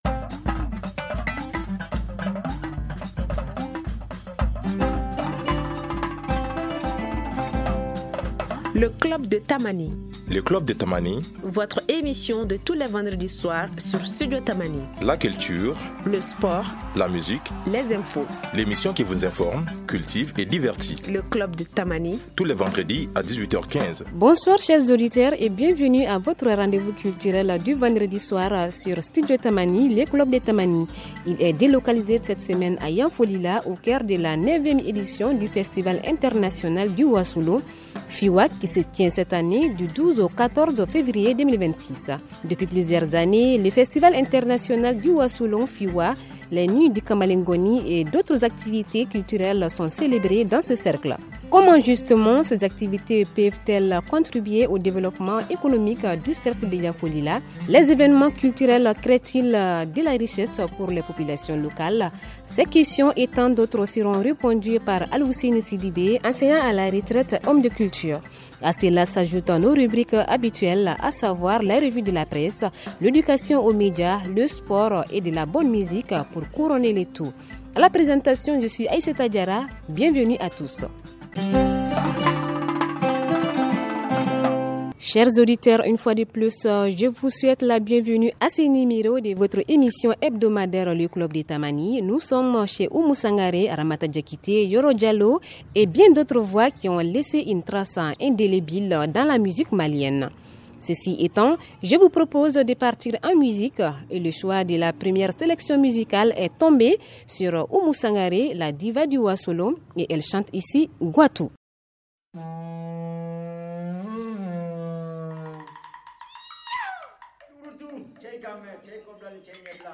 Le club de Tamani de cette semaine s’intéresse à la culture et à son apport au développement du cercle de Yanfolila. Depuis Yanfolila, au cœur de la 9ᵉ édition du festival international du Wassulu FIWA, nous allons tenter de savoir comment culturellement les activités comme le FIWA, le festival culturel du Wassulu et bien d’autres peuvent contribuer au développement économique du cercle de Yanfolila.